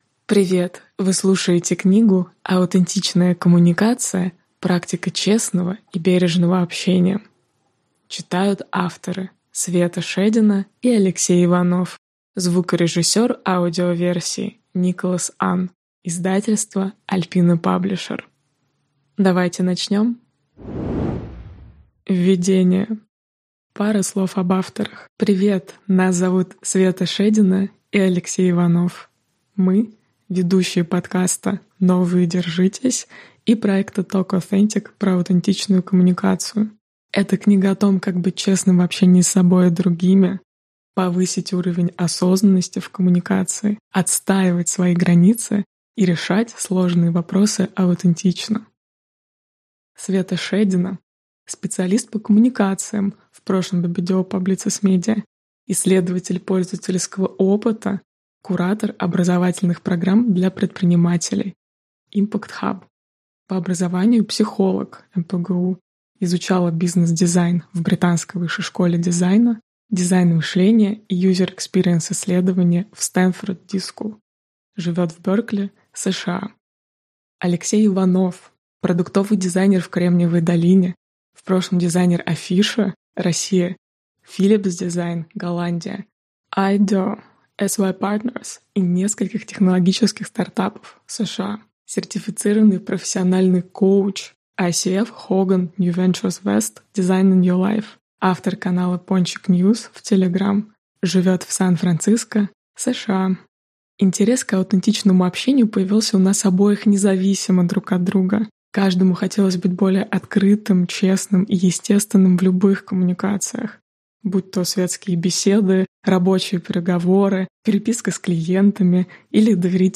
Аудиокнига Аутентичная коммуникация. Практика честного и бережного общения | Библиотека аудиокниг